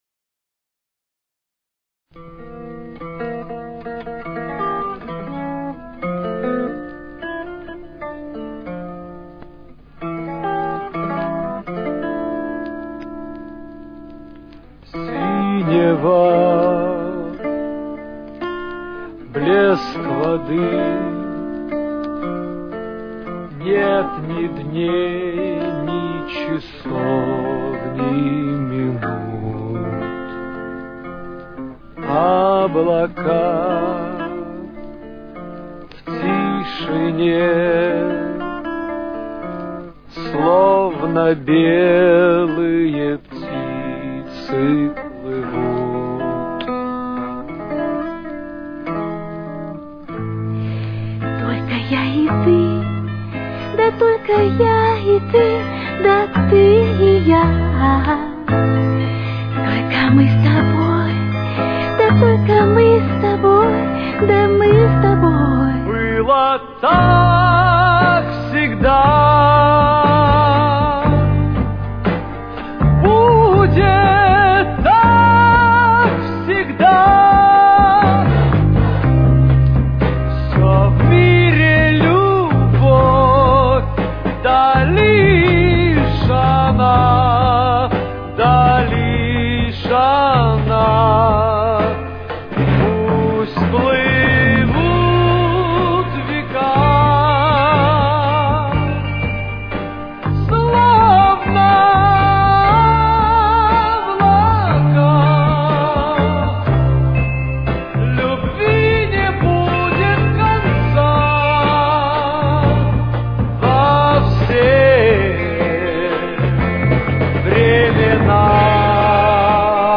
Темп: 60.